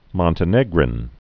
(mŏntə-nĕgrĭn, -nēgrĭn)